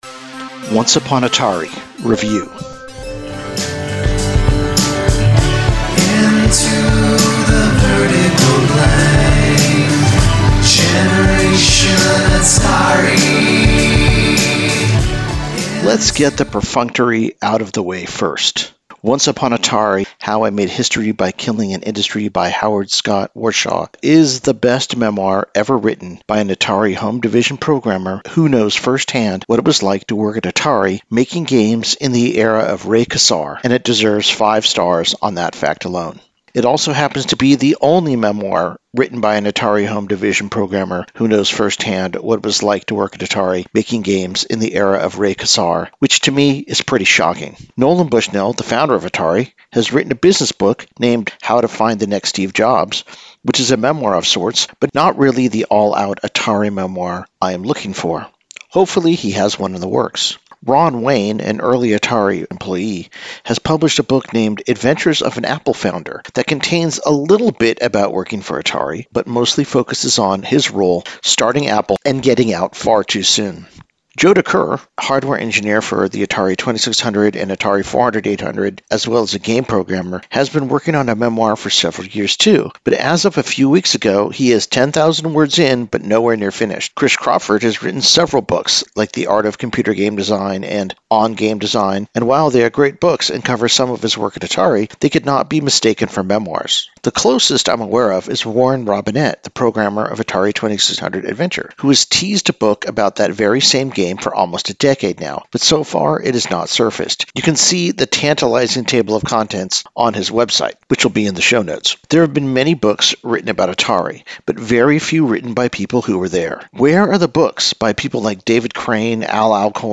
#106: Redemption : "Once Upon Atari" By Howard Scott Warshaw, Book Review